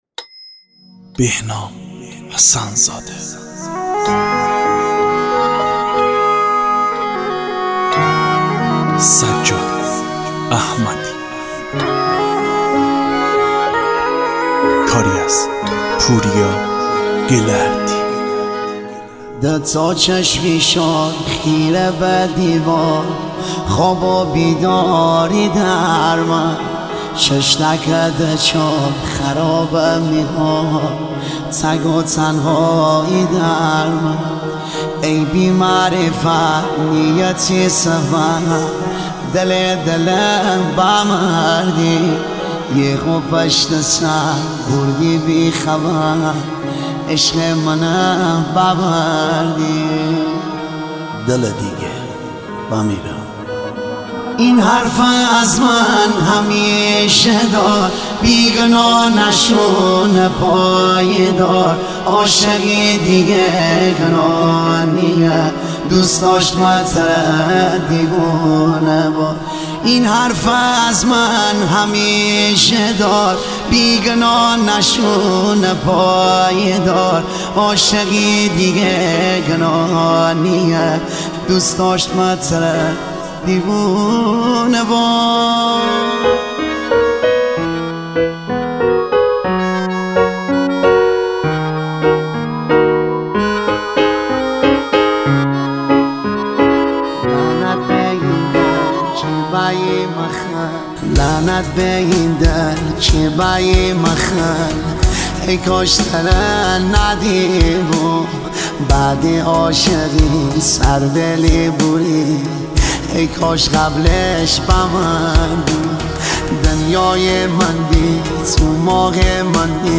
تک آهنگ
پاپ